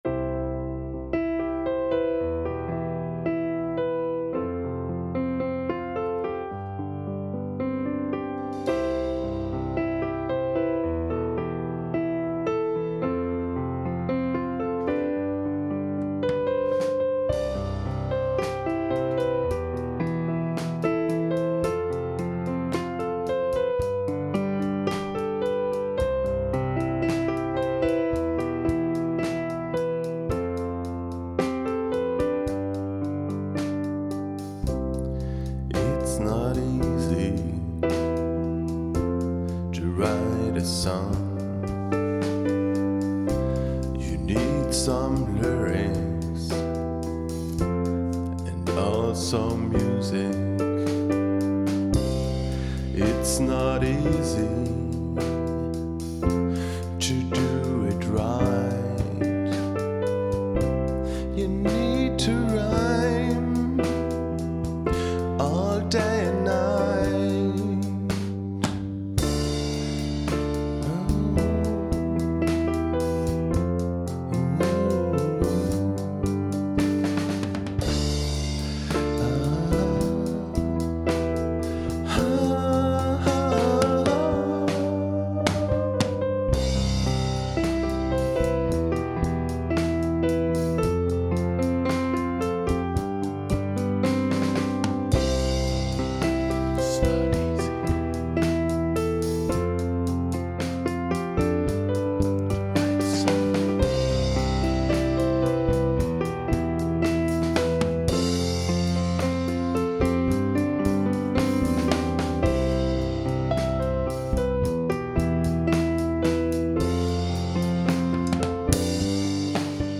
Es ging mir vor allem darum, meiner Kreativität freien Lauf zu lassen und gleichzeitig mein kleines Heimstudio auszuprobieren.
• Alles wurde an einem Tag geschrieben, gespielt, aufgenommen und produziert.